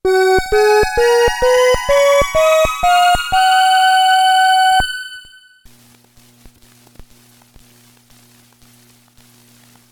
Each sample consists of a scale (F# to F#) with the violin and flute controls set to maximum, followed by another scale with all voices off (so you can hear only the noise).
The noise is completely unnoticeable when there are any notes playing, and nearly inaudible during quiet periods too. The violin voice is also a bit brighter, due to the less aggressive intermediate filter:
The noise is at a fairly consistent -80dB, with the fundamental still at -9dB, giving a signal-to-noise ratio of 71dB.
AfterNoiseReduction.mp3